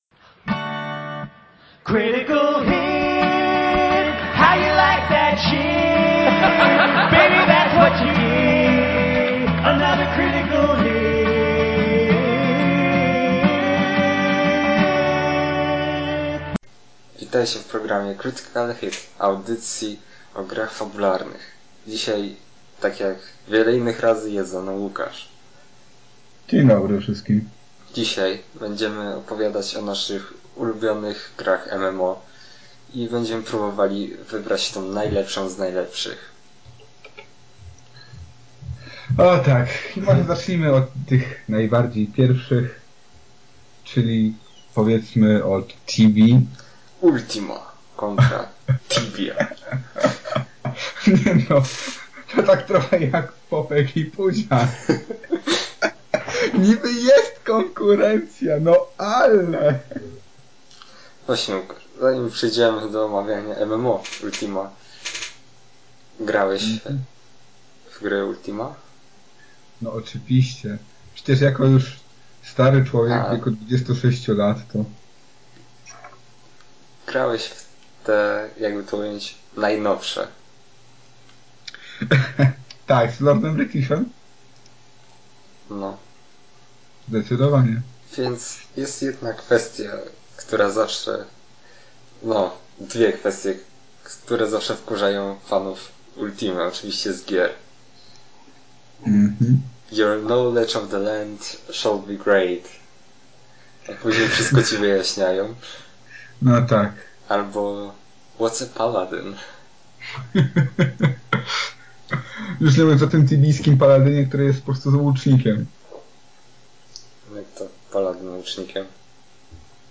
Po małych problemach z nagrywaniem opowiadamy o naszych doświadczeniach z mmo i o tym jakie są najlepsze.